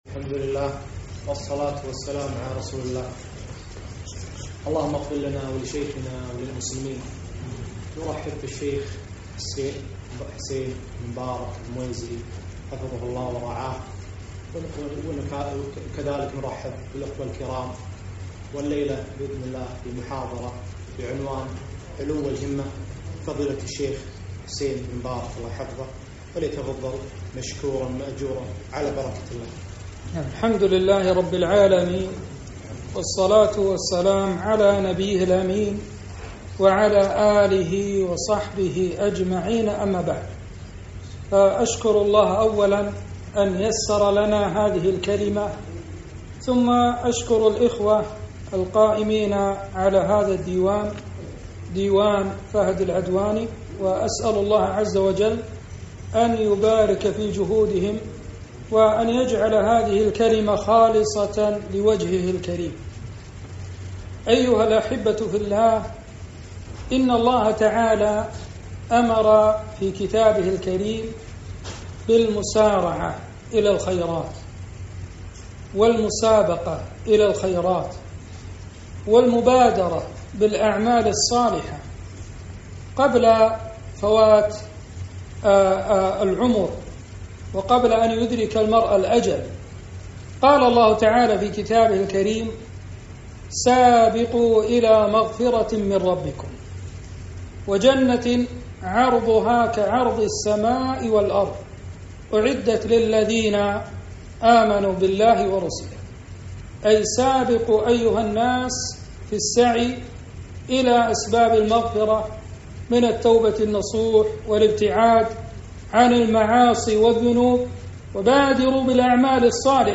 محاضرة - علو الهمة